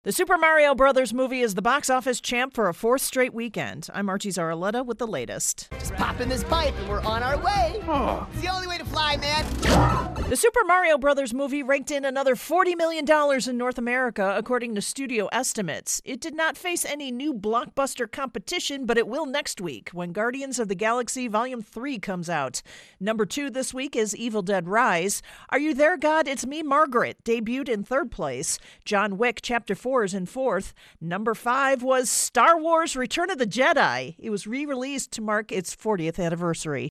AP correspondent
reports on Box Office.